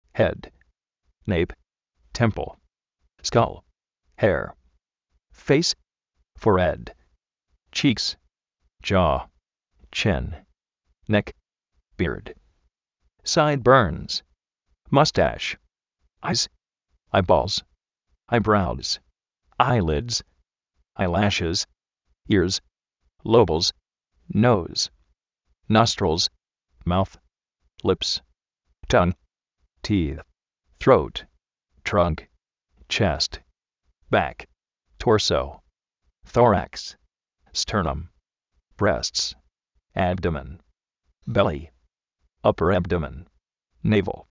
jéd
néip, témpl, skál, jé:r
féis, foréd, chí:ks,
áis, áibols, áibraus, áilids,áilashis
nóus, nóstrils